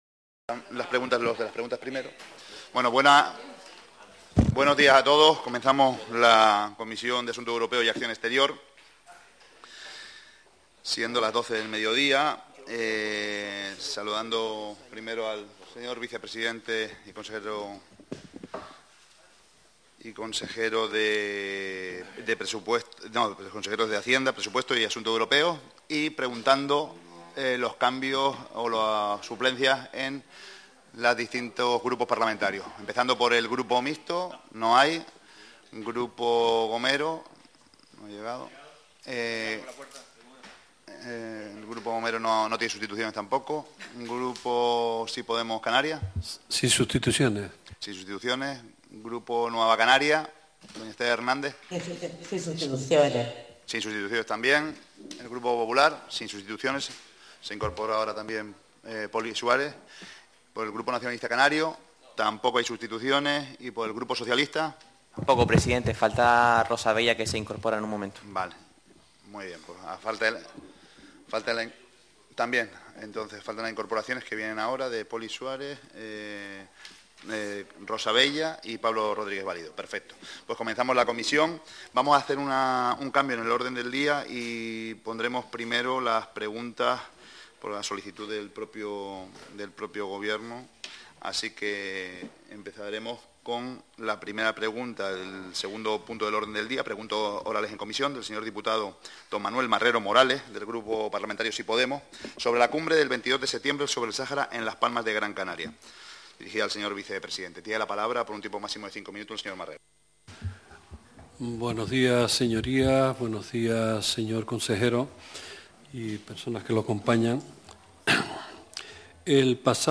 Grabación Sala de Biblioteca